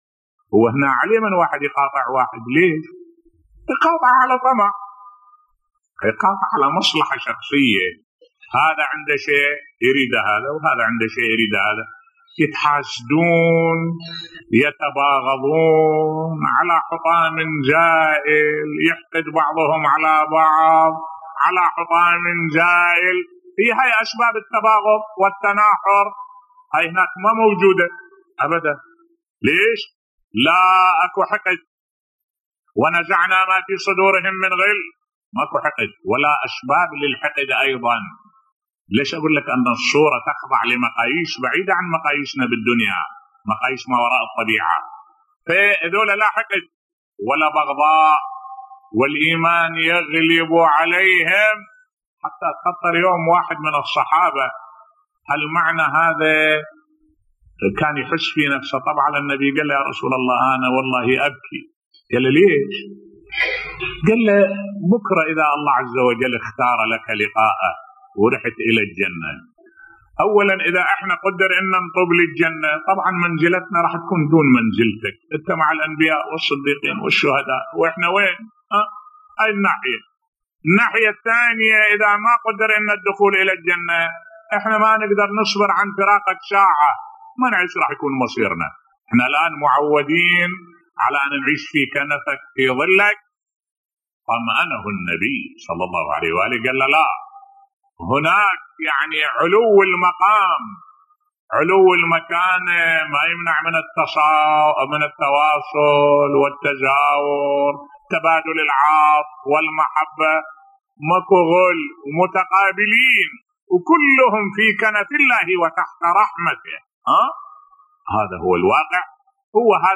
ملف صوتی الطمع والمصالح الشخصية بين الناس بصوت الشيخ الدكتور أحمد الوائلي